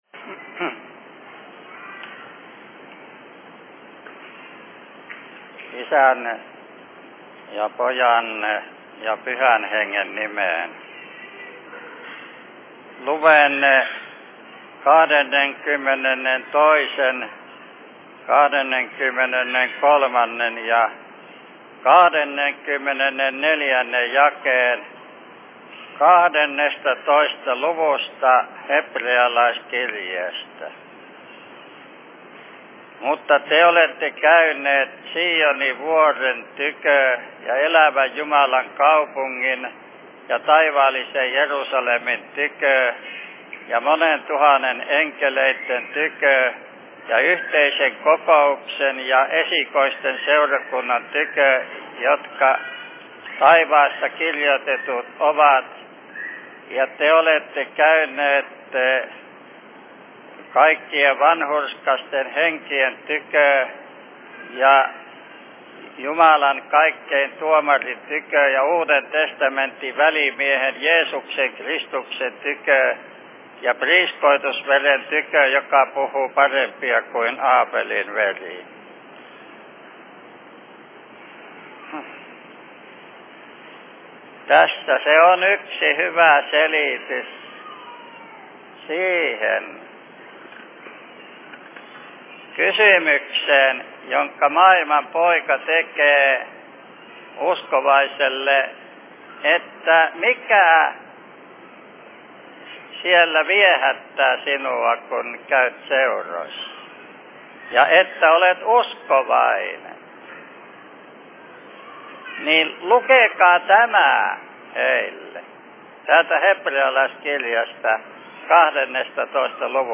Seurapuhe 07.05.1989
Paikka: Rauhanyhdistys Helsinki